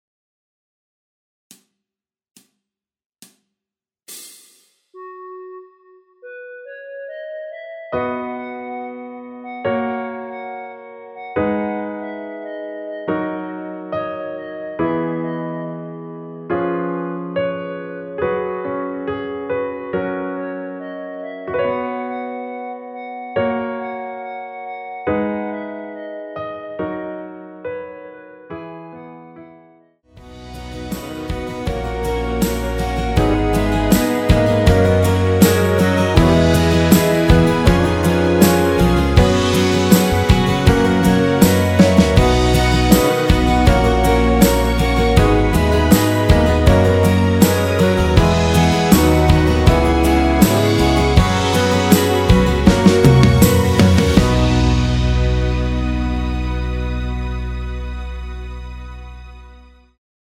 노래가 바로 시작하는곡이라 카운트 넣어 놓았으며
그리고 엔딩이 너무 길고 페이드 아웃이라 라랄라 반복 2번으로 하고 엔딩을 만들었습니다.
원키에서(+2)올린 멜로디 포함된 MR입니다.
앞부분30초, 뒷부분30초씩 편집해서 올려 드리고 있습니다.
중간에 음이 끈어지고 다시 나오는 이유는